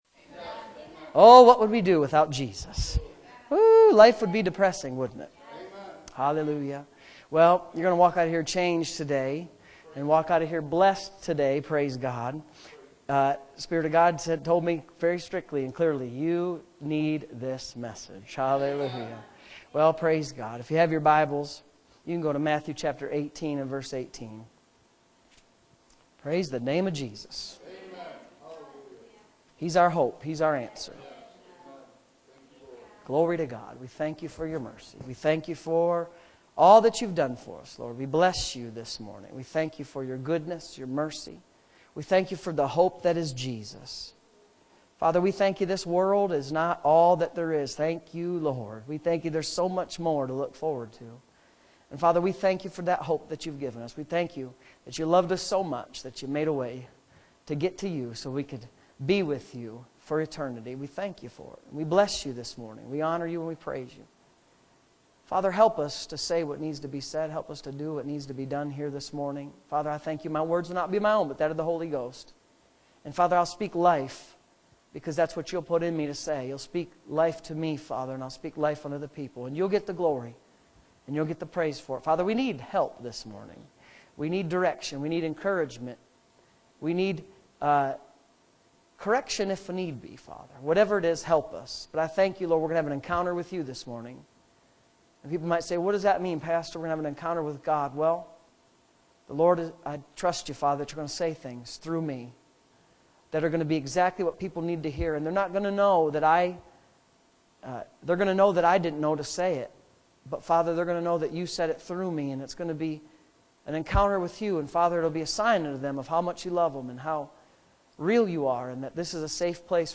Sunday Morning Services